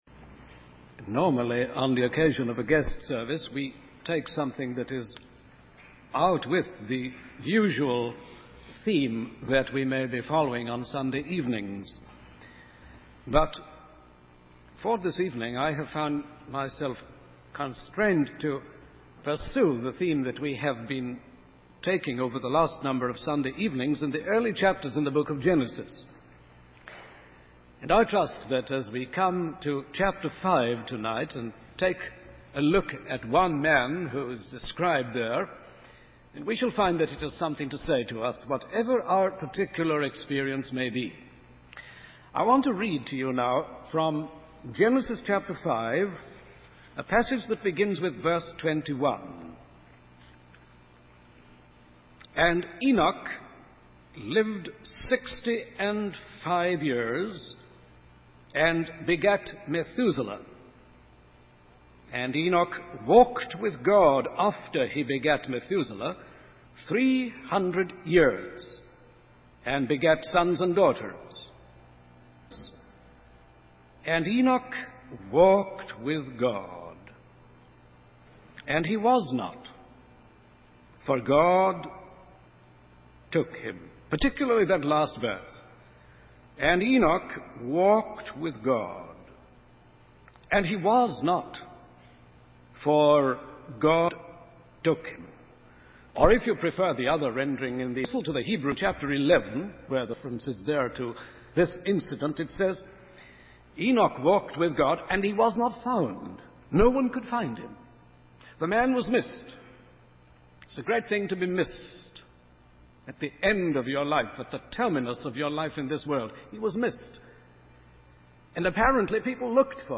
In this sermon, the preacher discusses the transformative power of walking with God. He uses the example of Zacchaeus, who was changed after spending just an hour with Jesus.